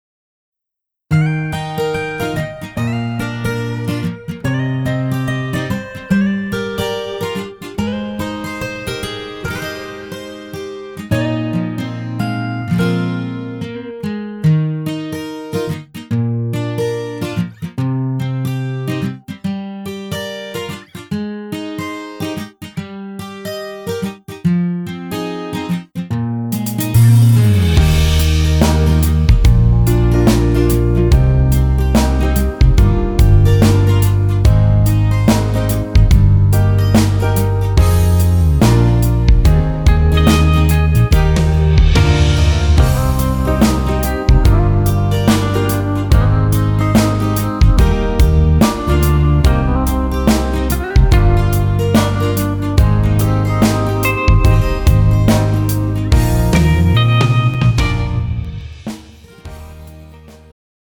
음정 여자-2키
장르 축가 구분 Pro MR